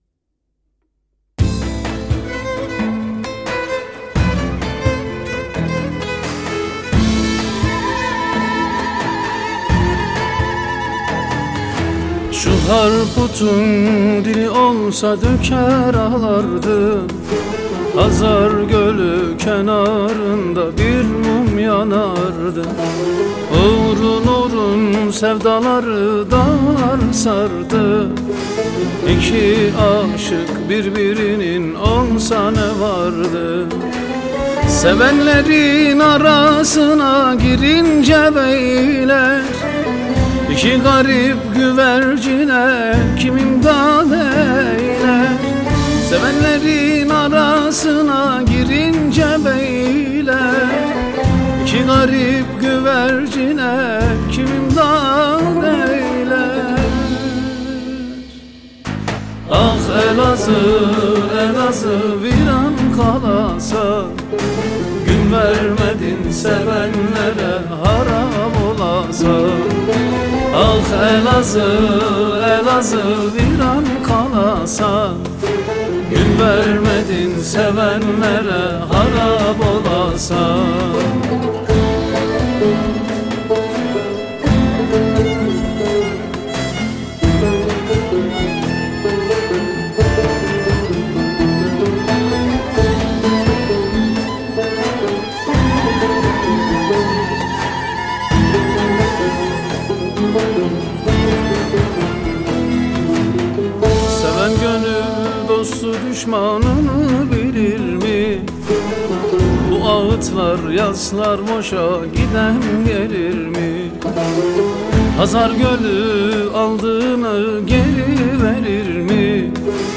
Eser Şekli : Halk Müziği